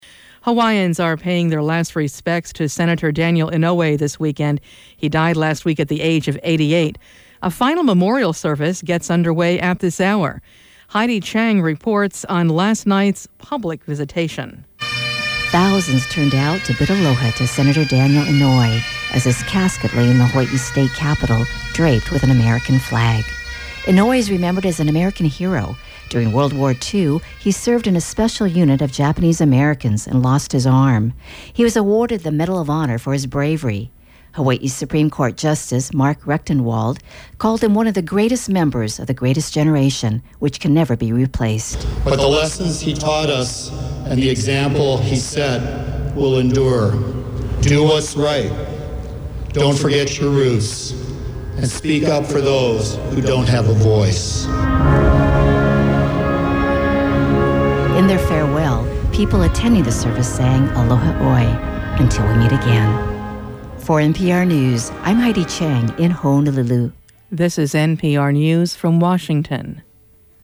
Aired on NPR